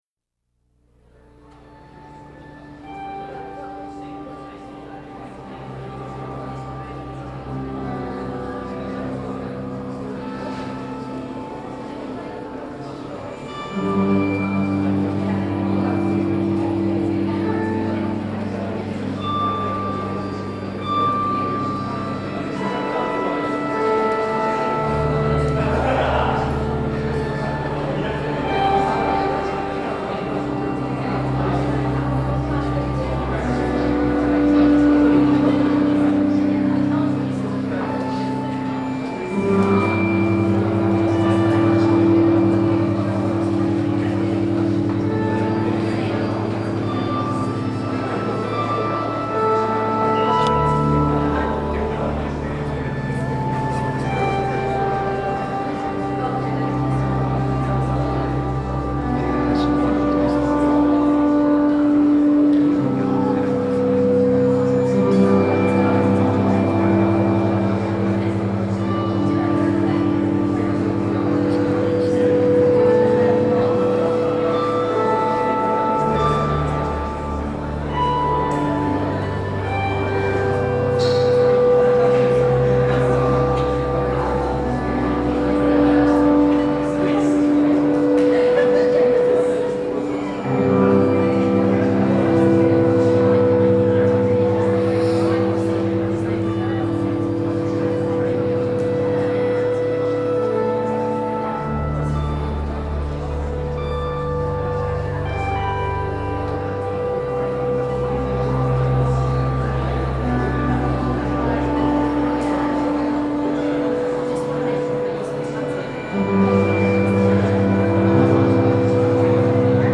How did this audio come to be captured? Reception Peformance Sunday 13th July 2003